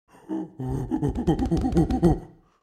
دانلود صدای میمون 2 از ساعد نیوز با لینک مستقیم و کیفیت بالا
جلوه های صوتی